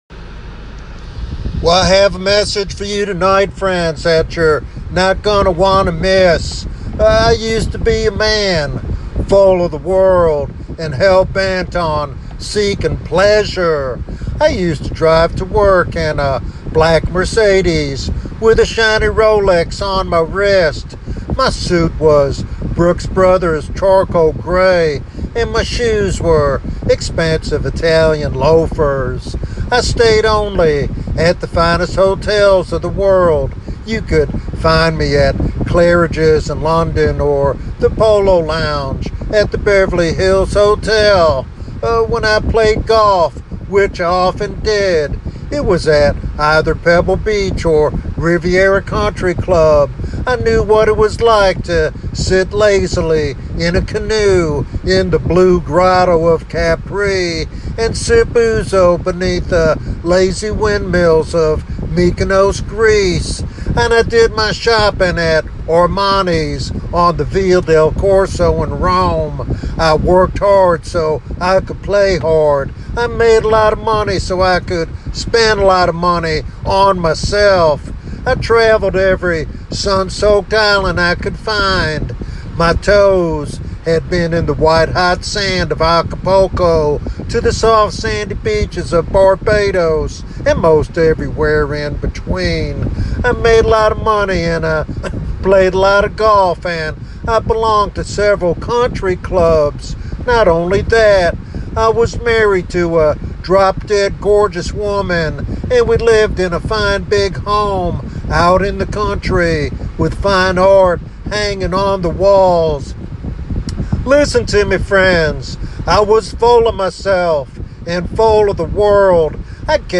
This evangelistic sermon challenges believers and seekers alike to examine their spiritual condition and respond to God's grace.